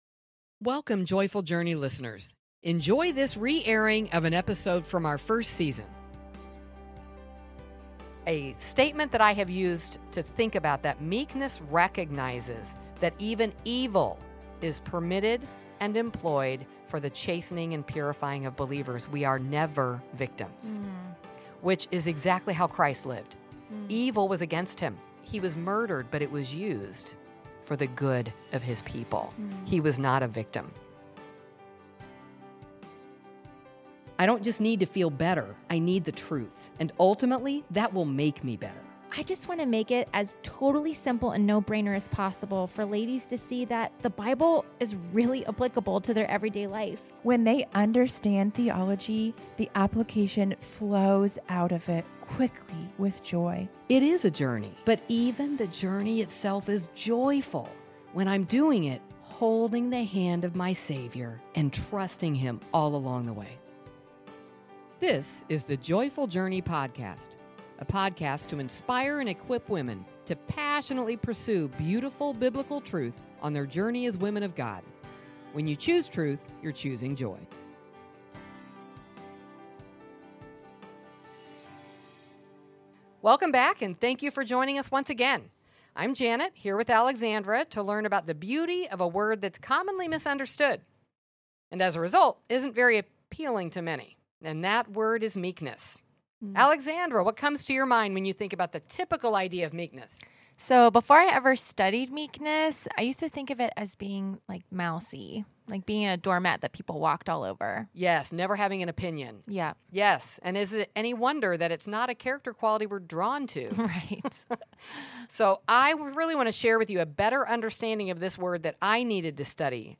Today’s culture often pushes back on the value of meekness, yet Jesus Himself presents meekness as a mark of true strength. Discover how understanding the gentle, yet powerful nature of biblical meekness can fill life with hope. In this uplifting conversation